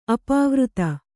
♪ apāvřta